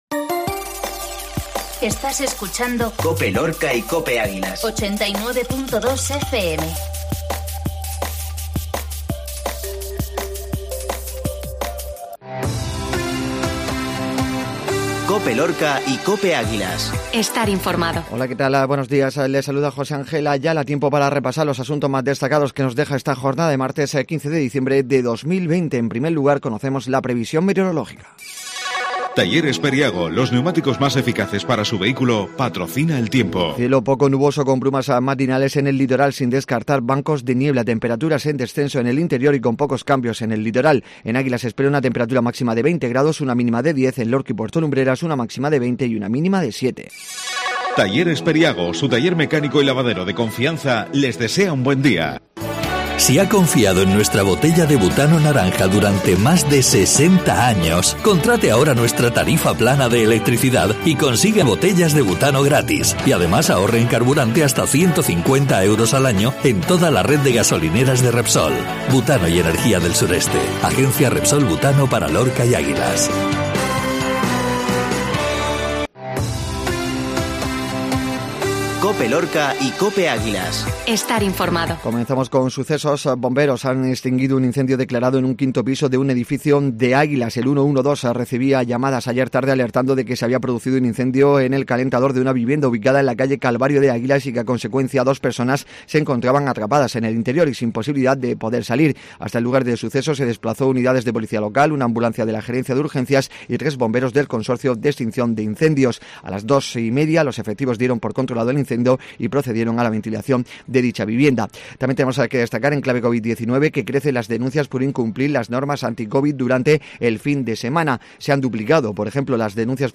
INFORMATIVO MATINAL MARTES